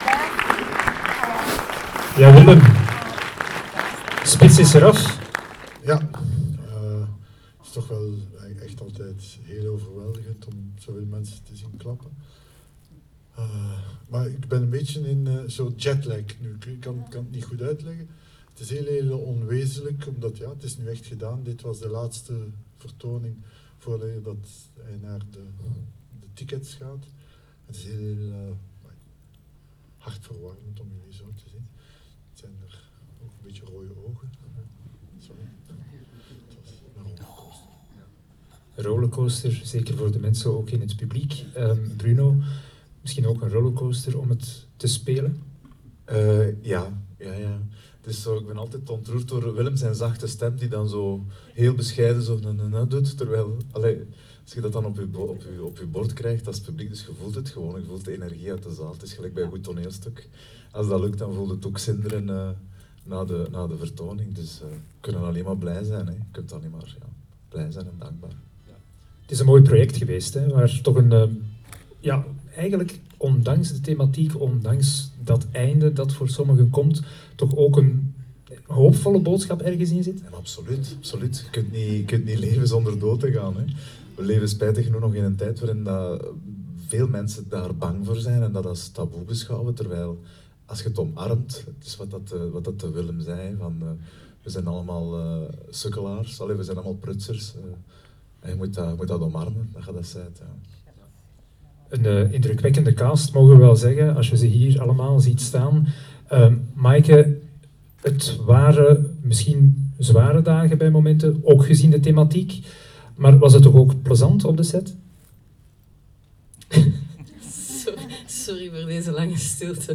Hieronder een impressie van de avant-première.
Na de voorstelling volgde een Q&A met de hele ploeg vooraan in de zaal.